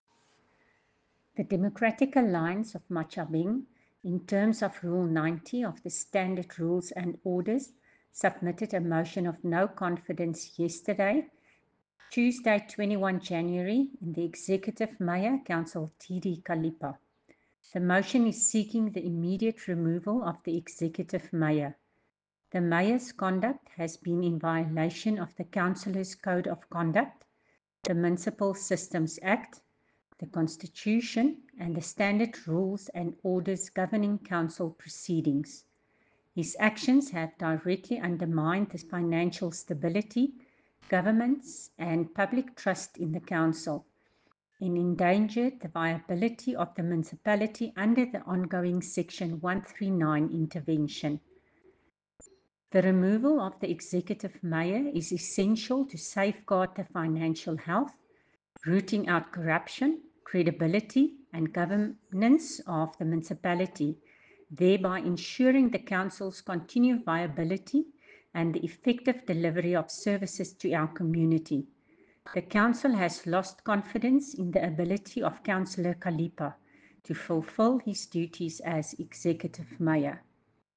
Afrikaans soundbites by Cllr Coreen Malherbe and